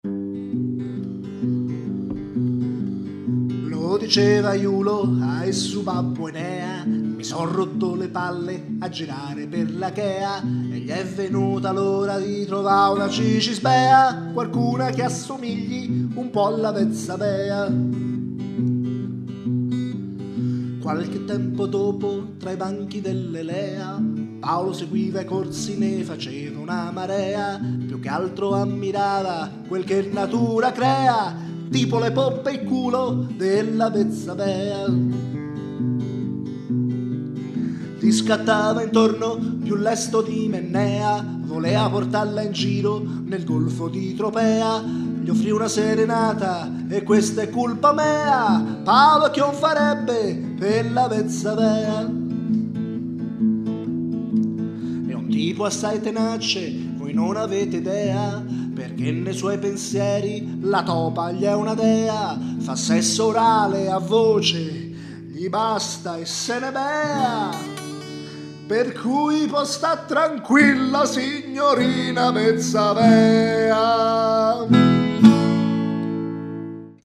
Una romantica ballata